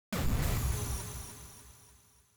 ufo_destroy_003.wav